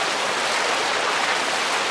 KART_Skidding_On_Grass.ogg